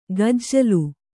♪ gajjalu